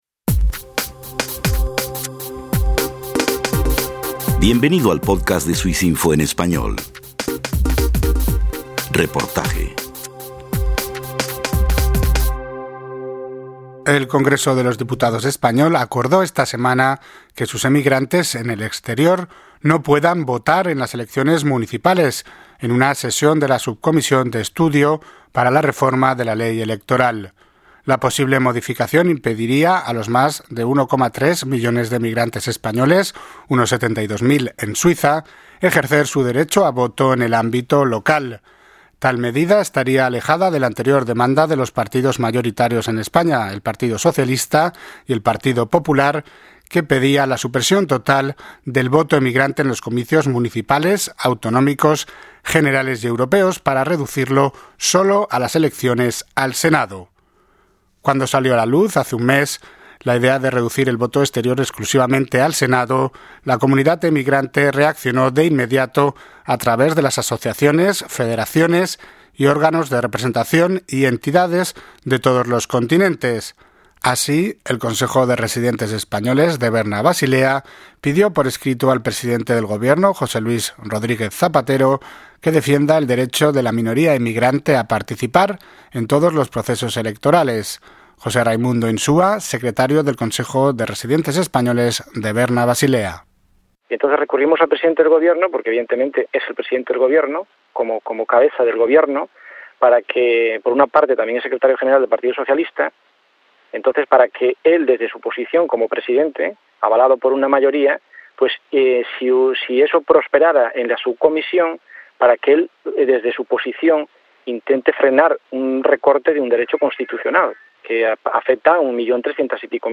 Ante la posible reforma de la Ley Electoral acordada en el Congreso de los Diputados español. Un reportaje